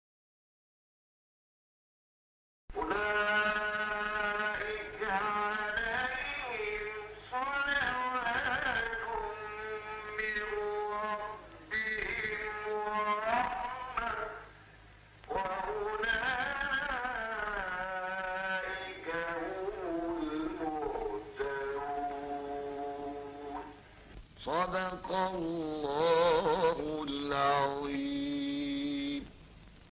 برچسب ها: خبرگزاری قرآن ، ایکنا ، فعالیتهای قرآنی ، مقاطع صوتی ، فراز صوتی ، تلاوت ، راغب مصطفی غلوش ، شحات محمد انور ، محمد الفیومی ، محمود شحات انور ، محمد عبدالعزیز حصان ، متولی عبدالعال ، مقطع میلیونی مصطفی اسماعیل ، قرآن